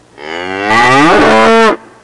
Cow Sound Effect
Download a high-quality cow sound effect.
cow.mp3